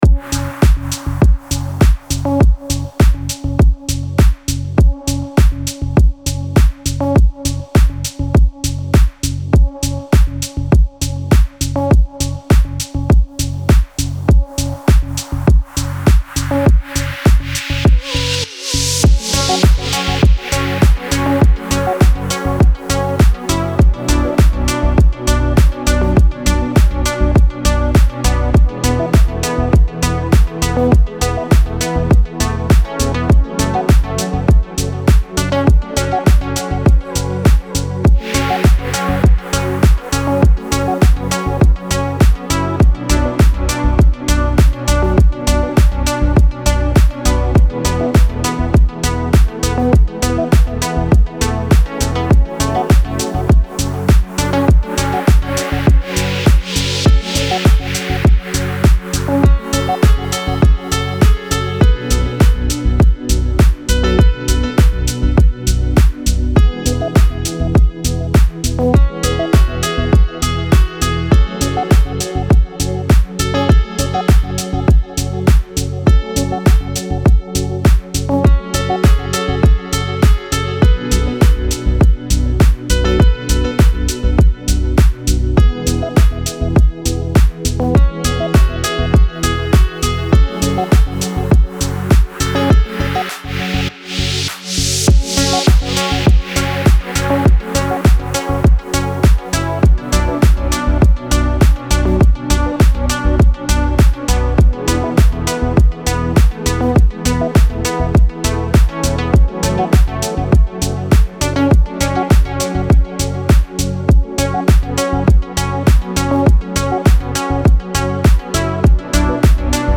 موسیقی کنار تو
موسیقی بی کلام دیپ هاوس ریتمیک آرام